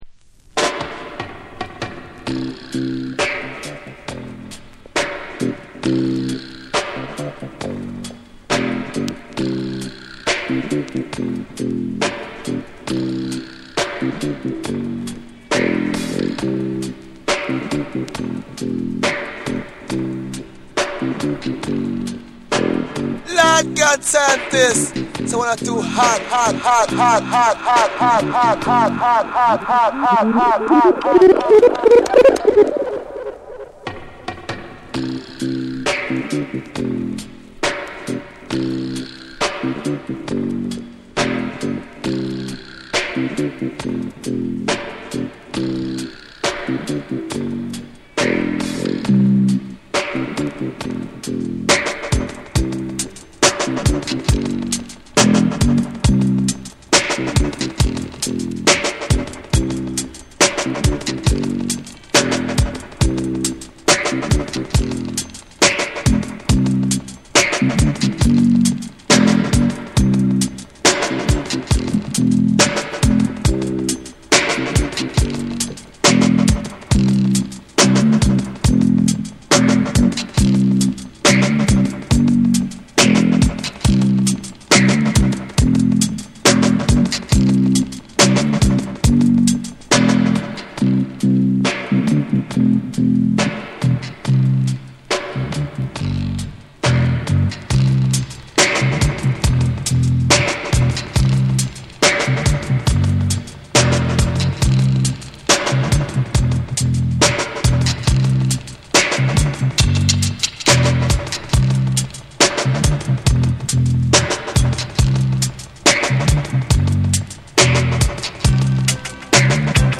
エコーとリバーブが縦横無尽に飛び交い、闇とユーモアが同居する音響世界を展開する。
REGGAE & DUB